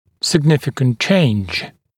[sɪg’nɪfɪkənt ʧeɪnʤ][сиг’нификэнт чэйндж]значительное изменение, значительные изменения